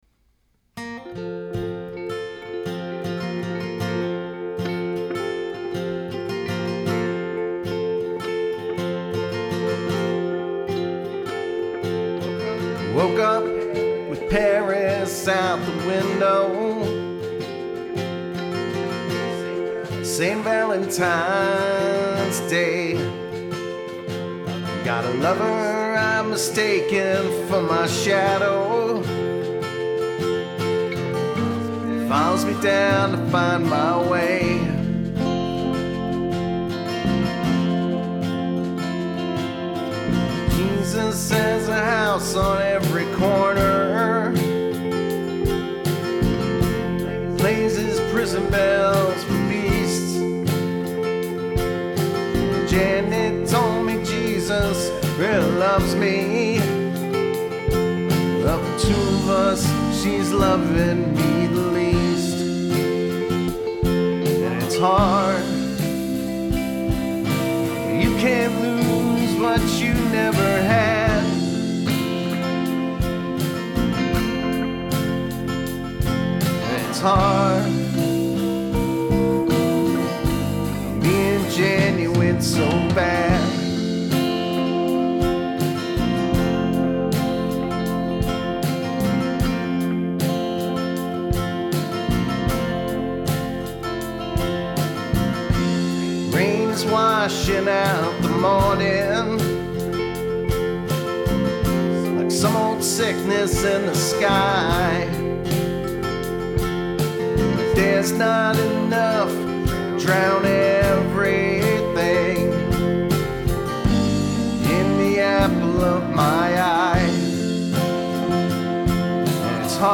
music is organic, honest, intimate and engaging